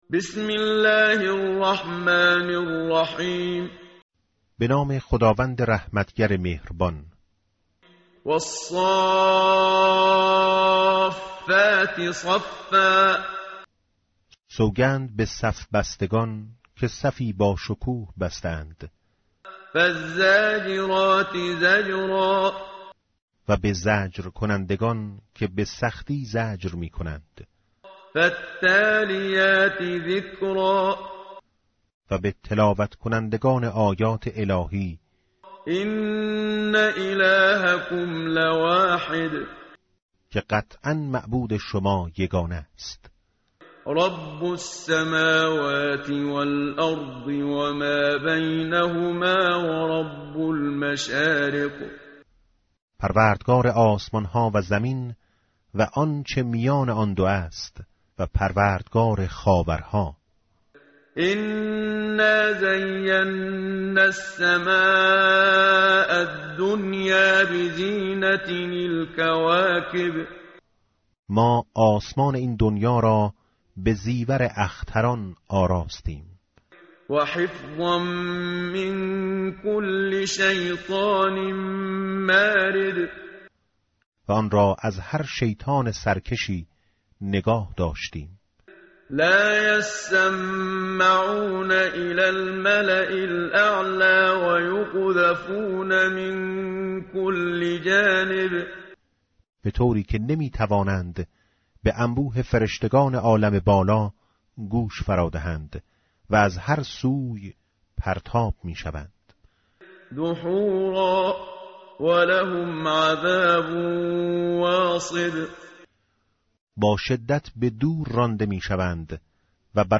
tartil_menshavi va tarjome_Page_446.mp3